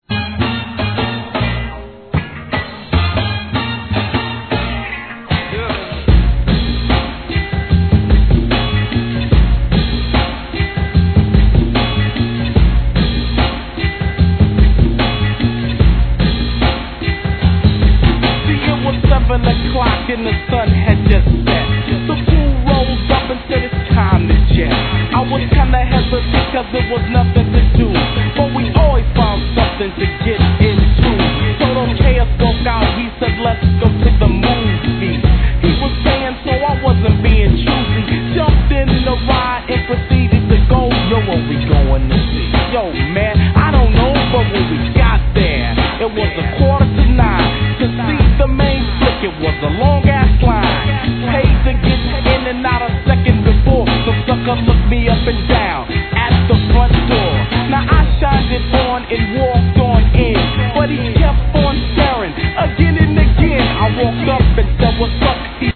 G-RAP/WEST COAST/SOUTH
サイレンのように鳴り響くシンセに誰もがやられたことでしょう!!